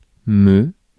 Ääntäminen
France (Paris): IPA: [mø]